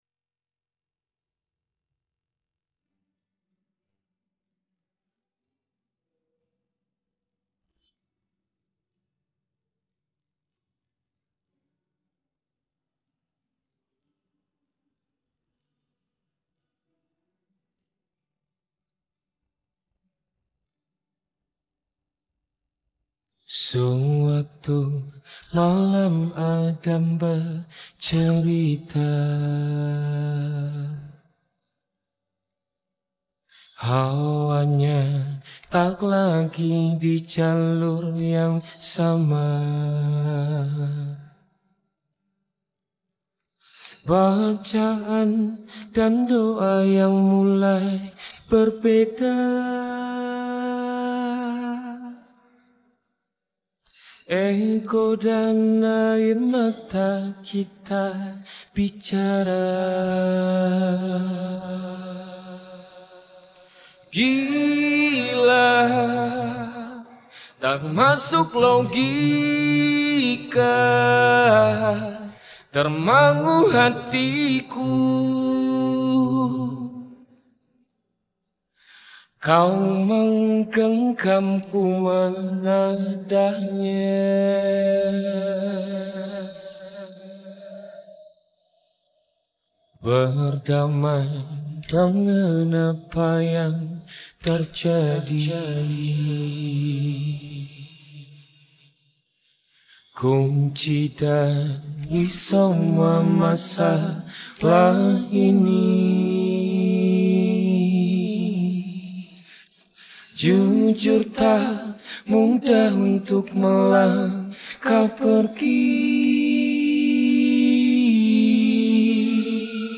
Bagian Vokal